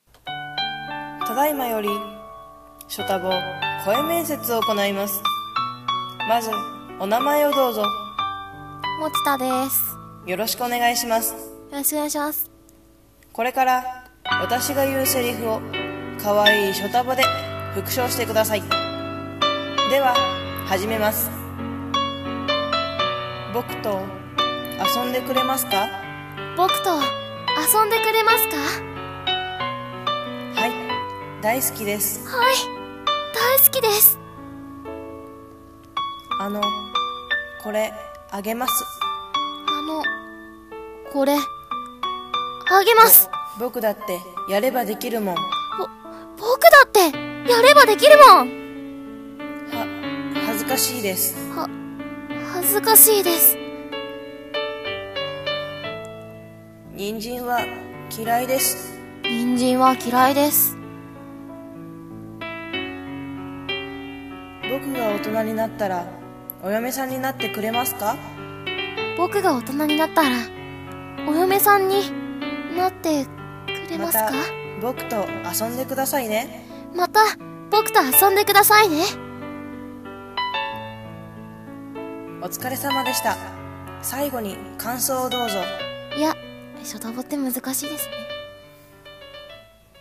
ショタボ声面接